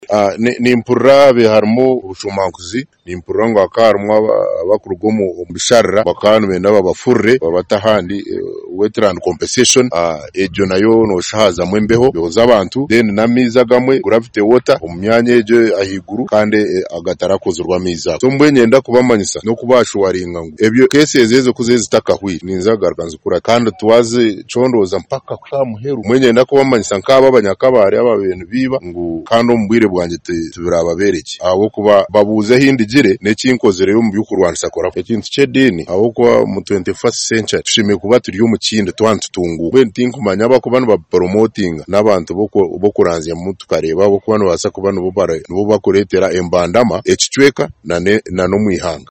Taking over the reins, the new RDC, Retired Major Godfrey Katamba, pledged a strong stance against corruption, sectarianism, and environmental degradation issues he described as stumbling blocks to Kabale’s development.
CUE IN RTD MAJ KATAMBA ON CORRUPTION…ENG